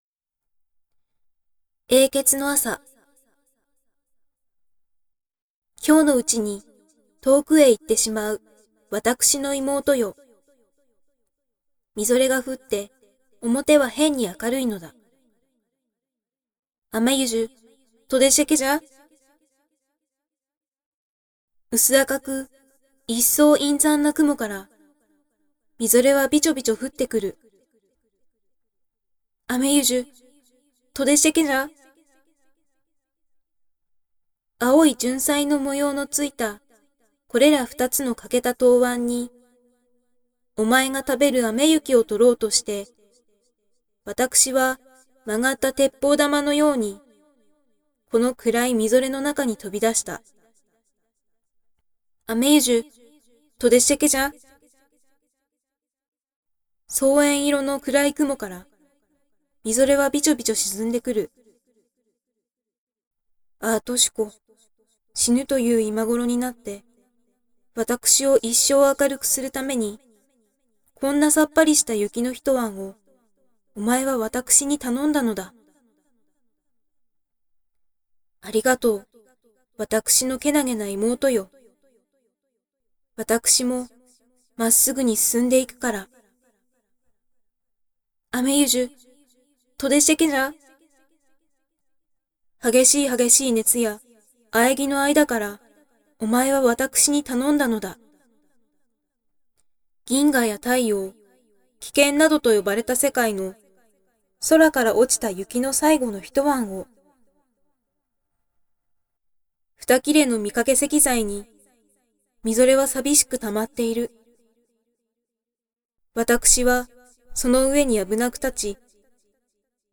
※さいごから2行目の「資糧」について「かて」と読む説と「しりょう」と読む説があるようですが、私は「しりょう」と読みました。
今朝起きて窓から外見たら雪が積もってて、水気が多めのﾍﾞｼｬｯとした雪だったもんだから「“永訣の朝”みたいじゃん！！！！」ってなって、勢いで朗読してみてしまった←